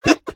Minecraft Version Minecraft Version 25w18a Latest Release | Latest Snapshot 25w18a / assets / minecraft / sounds / entity / witch / ambient5.ogg Compare With Compare With Latest Release | Latest Snapshot